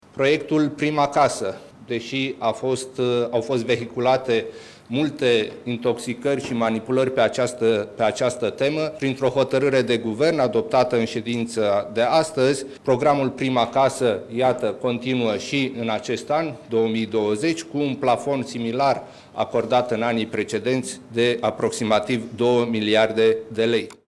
Șeful Cancelariei premierului, Ionel Dancă, a anunțat continuarea programului și a spus că pe acest subiect “au fost vehiculate multe intoxicări și manipulări”: